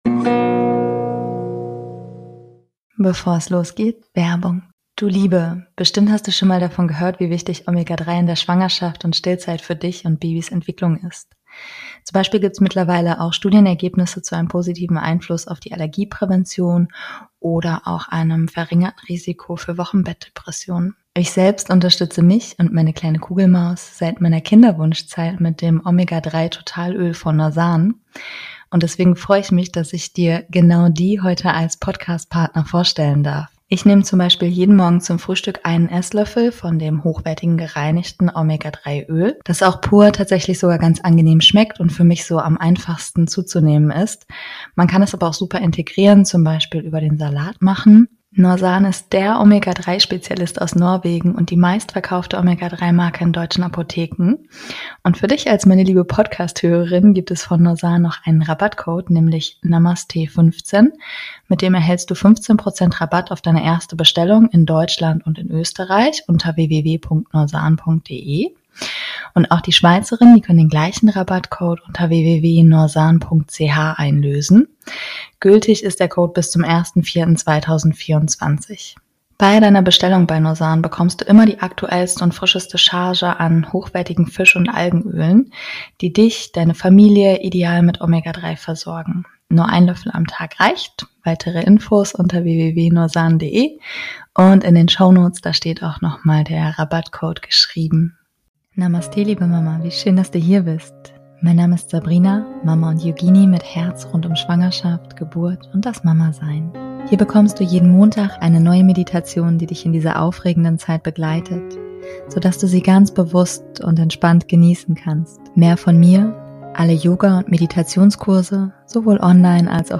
In dieser Meditation gehst du ganz in die Dankbarkeit für das was, dein Beckenboden gerade eigentlich großartiges leistet und übst dich mit ihm zu verbinden.